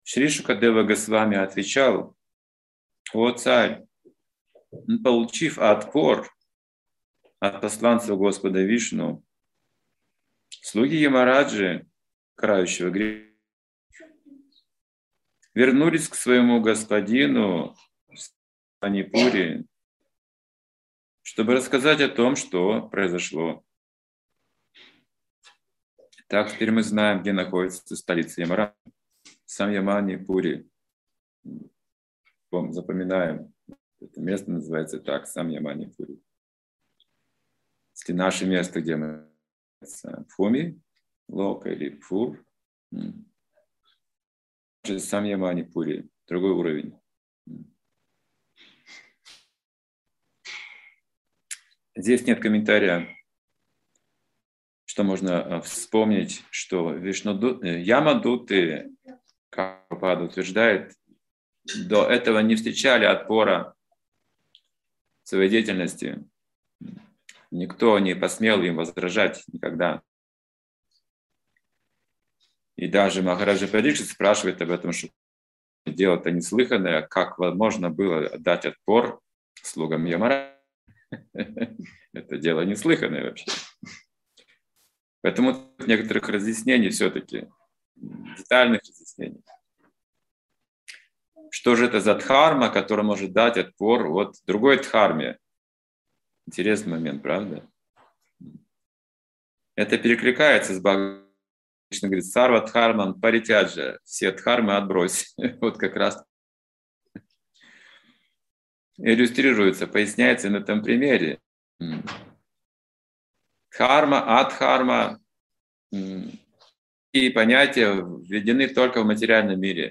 Лекции